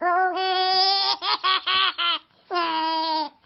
peekaboo1.ogg